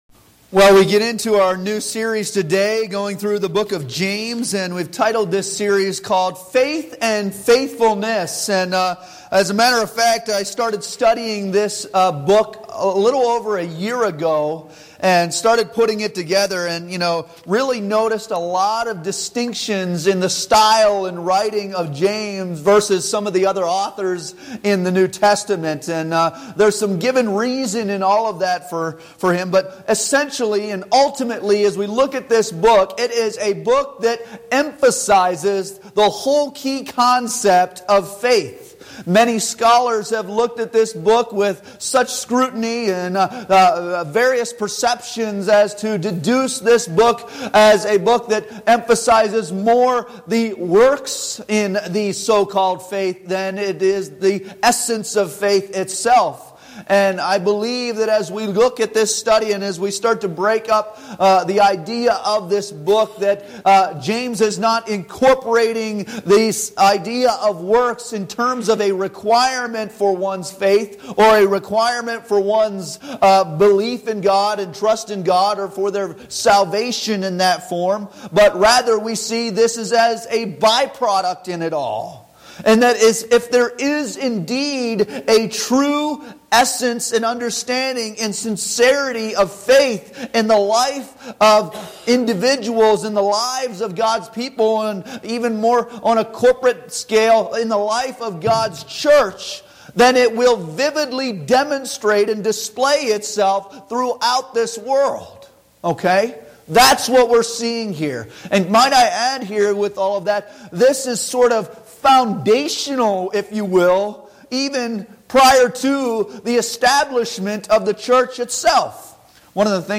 James 1:1-4 Service Type: Sunday Morning Worship Bible Text